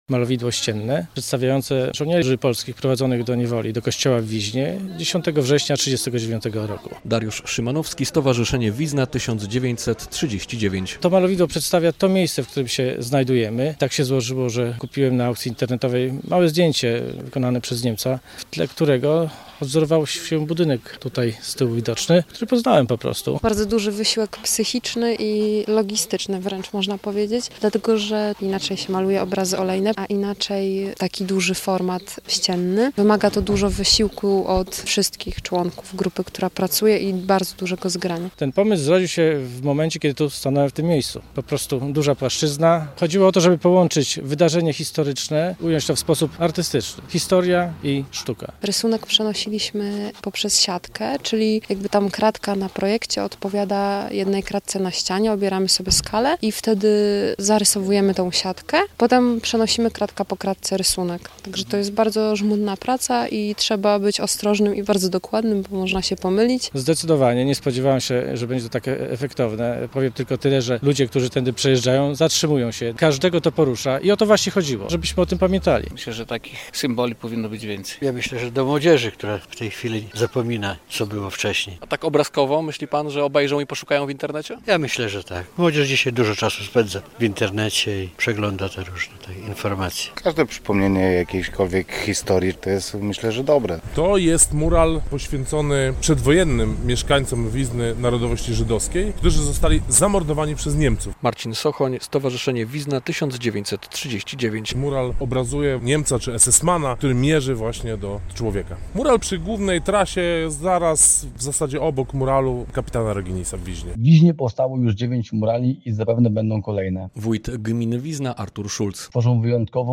Murale w Wiźnie - relacja